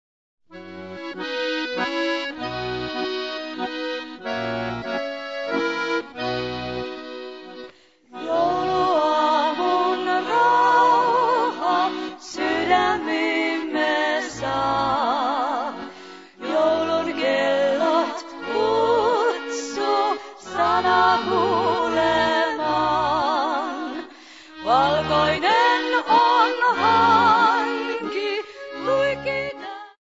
Laulut triona